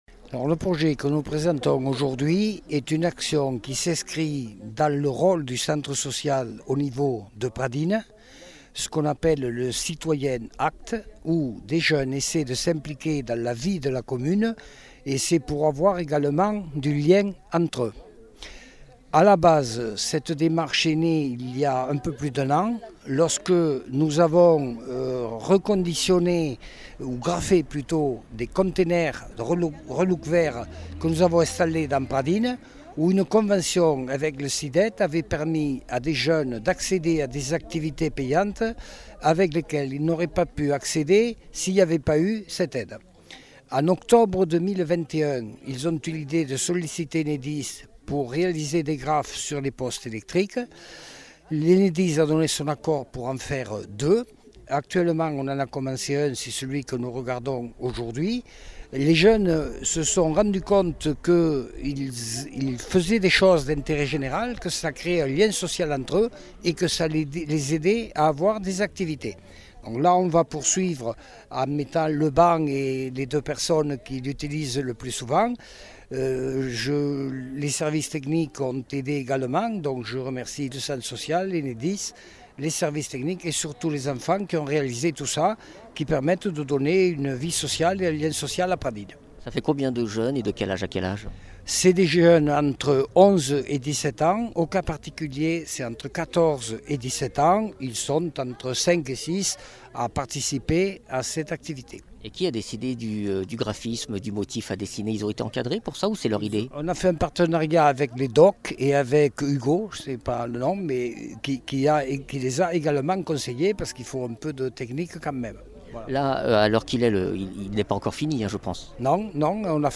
Interviews
Invité(s) : Denis Marre, Maire de Pradines